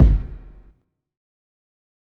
HFMKick1.wav